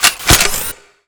sci-fi_weapon_reload_03.wav